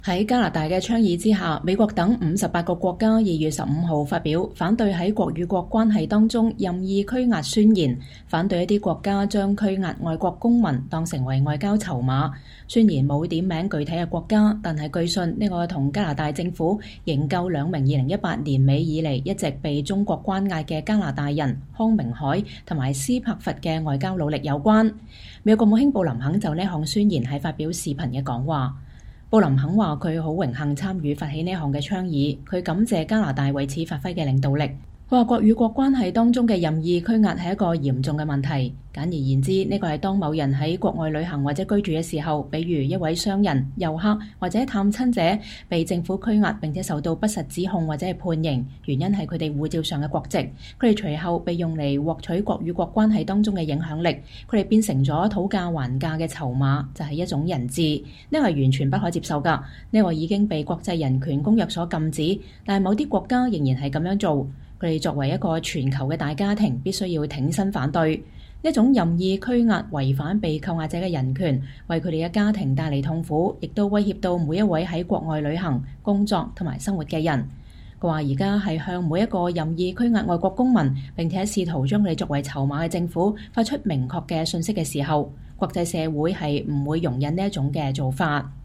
美國國務卿布林肯就這項宣言發表視頻講話。
布林肯國務卿就58國簽署《反對國與國關係中任意拘押宣言》發表視頻講話